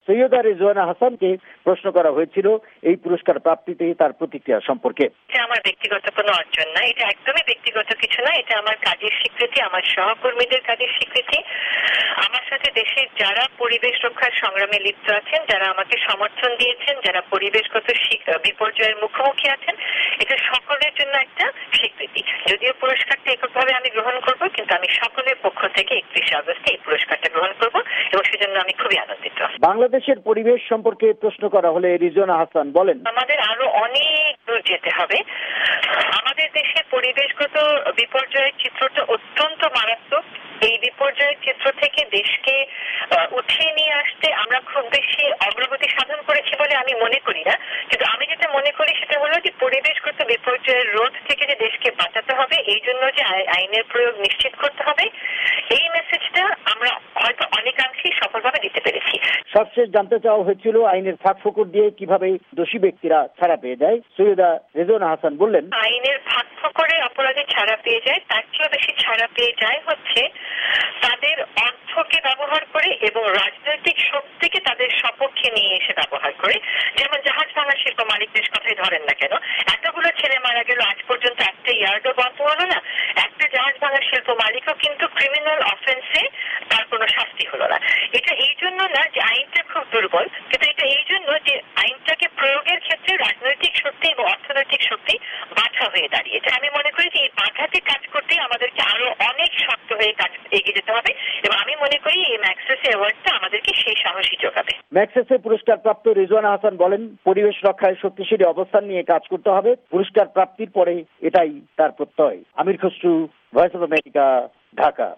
সৈয়েদা রিজওয়ানা হাসানের সাক্ষাত্কার